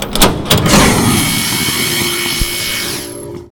vent.wav